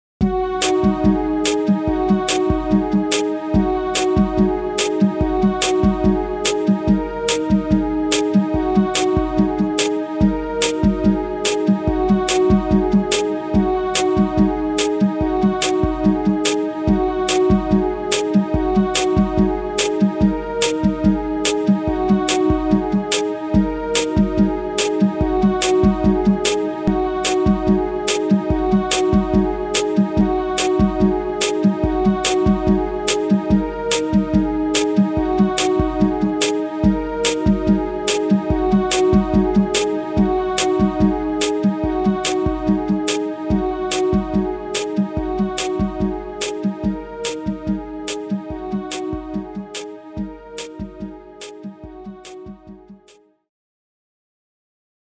Retro track for transitions & stingers.